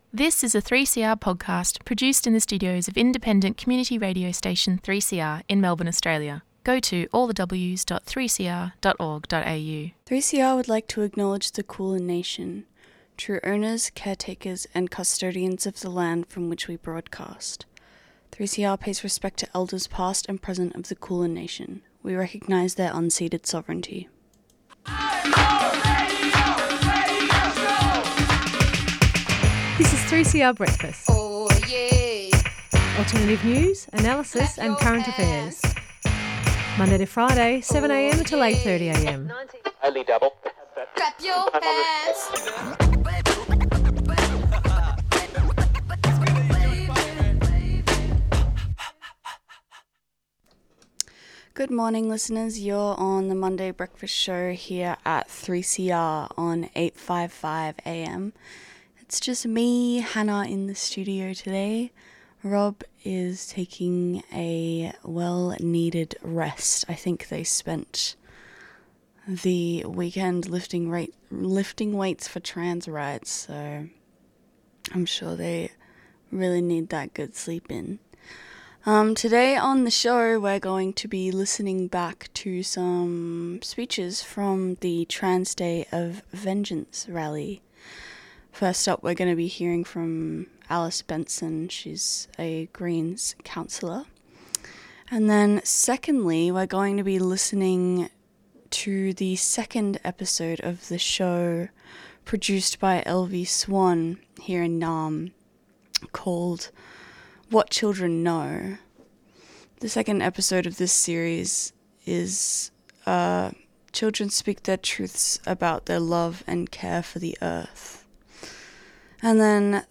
Headlines: "Pressure Off the Pump": Free Public Transport for all Victorians"Disrespected and Fed Up": Teachers Vow to Escalate Strike Action“Trans Day of Audibility”: Joy as Resistance on the Airwaves Segments: - A Speech from Saturday's Trans Day of Vengeance Rally, we firstly heard from Greens counsellor Alice Benson.